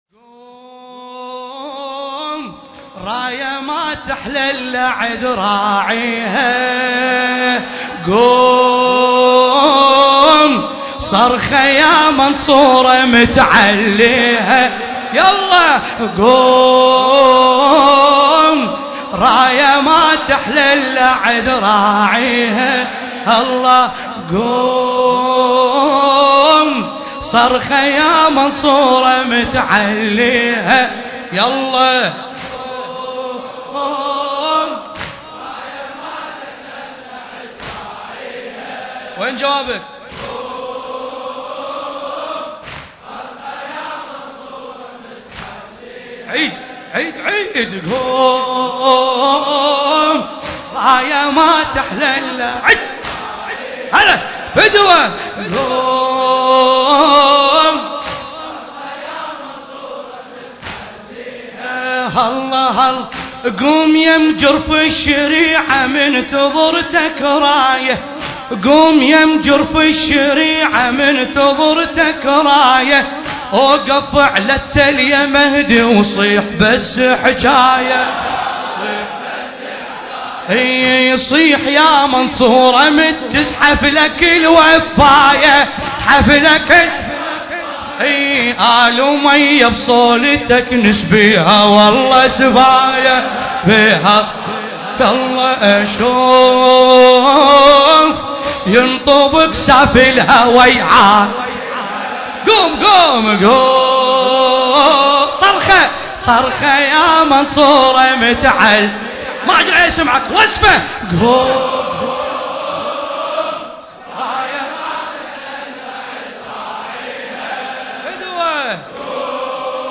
حسينية المرحوم داود العاشور